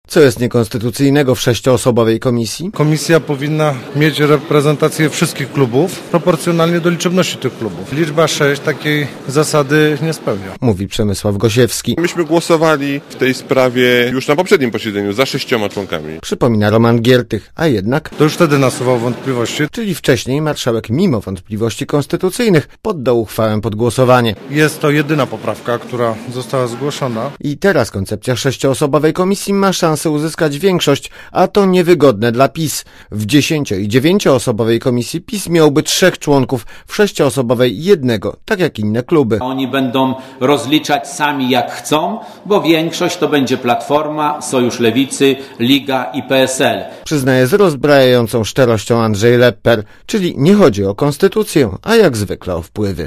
Źródło: Archiwum Relacja reportera Radia ZET Oceń jakość naszego artykułu: Twoja opinia pozwala nam tworzyć lepsze treści.